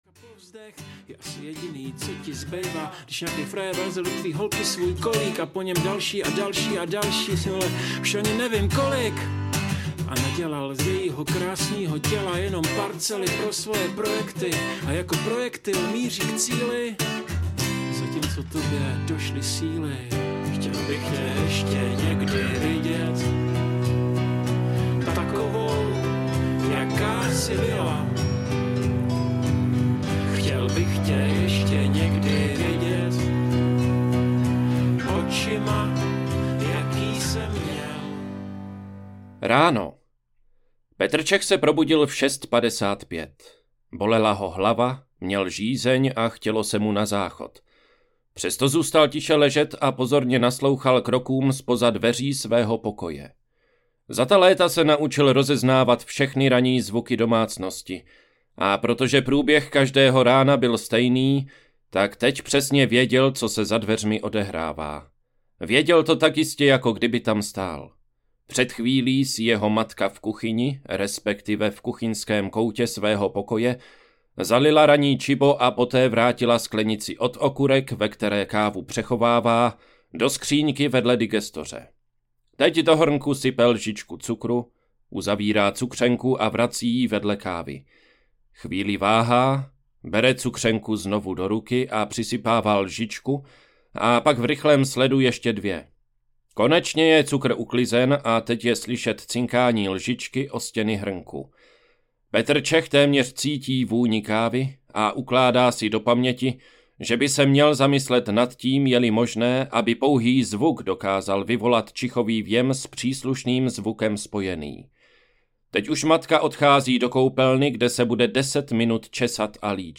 Den, kdy se potkali audiokniha
Ukázka z knihy